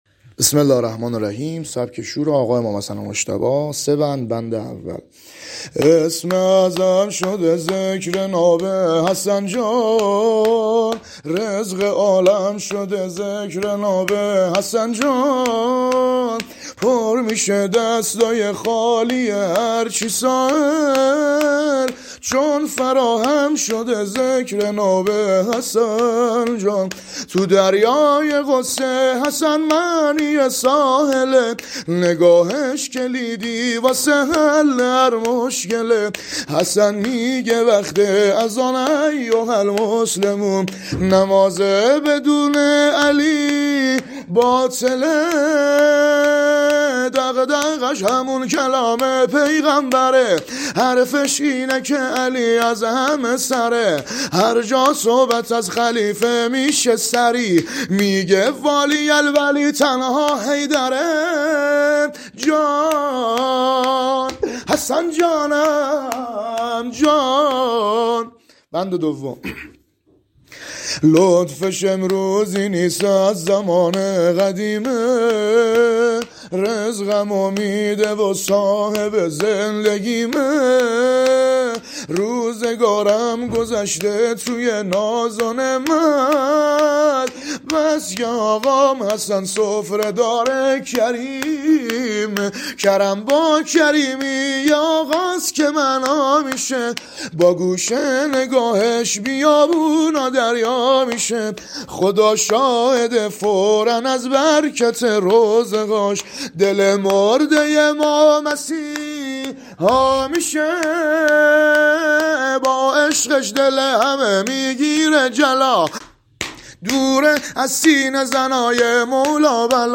شور امام حسن مجتبی علیه السلام -(اسم ِ اعظم شده ذکرِ نابِ حسن(ع)جان)